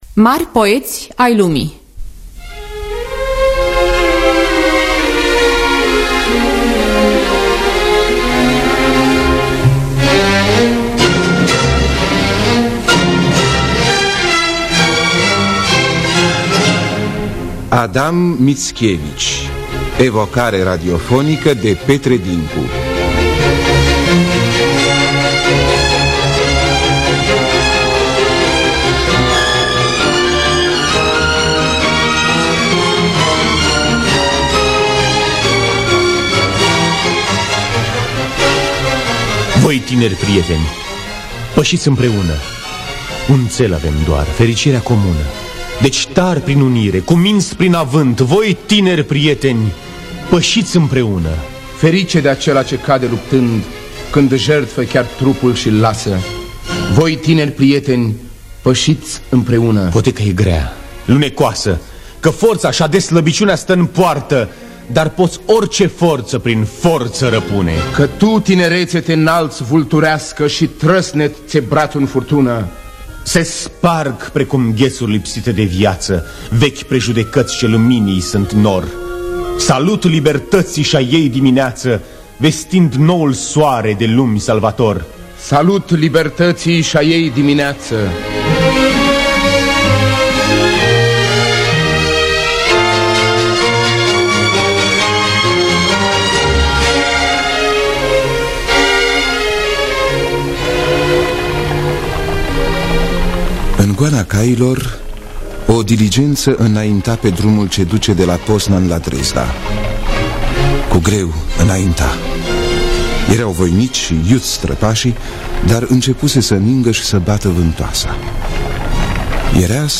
Scenariu radiofonic de Petre Dincu.